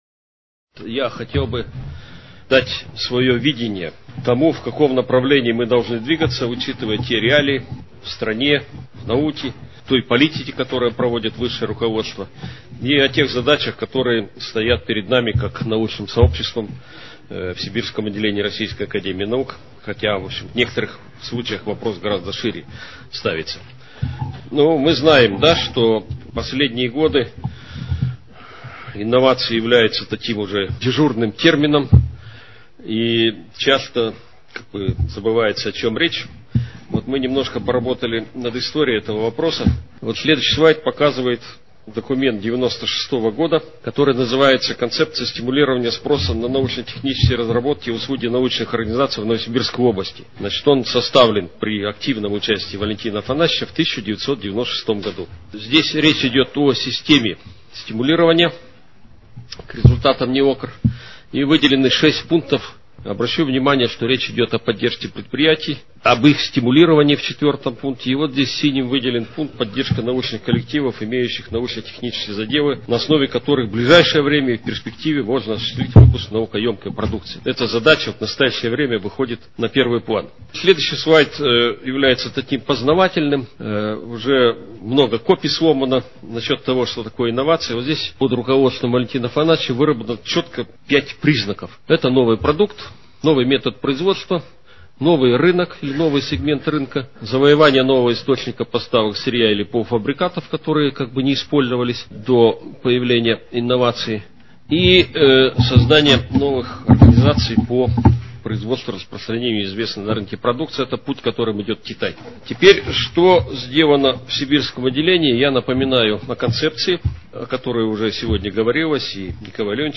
14 июля 2011 г. на расширенном заседании Президиума СО РАН председатель Отделения академик А.Л. Асеев озвучил доклад, посвященный программам инновационного развития Сибирского отделения РАН, научных центров и академгородков.